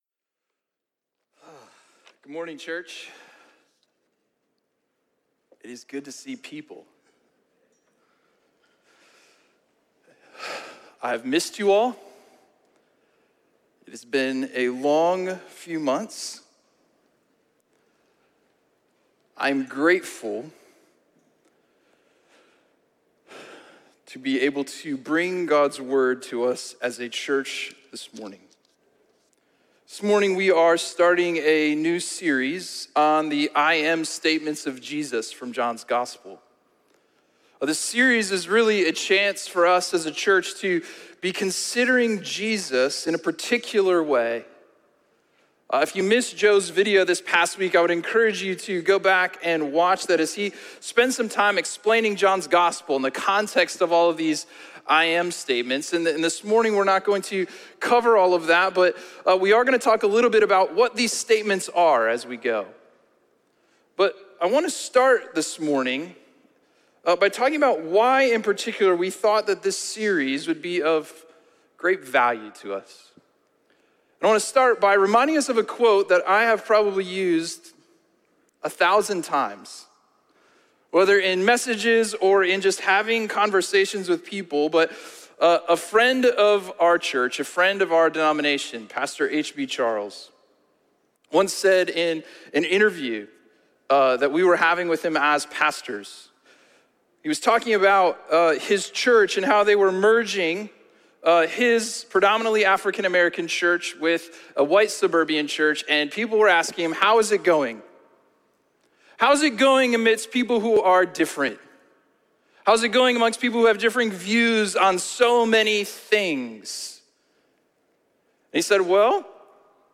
A message from the series "1-1-Six."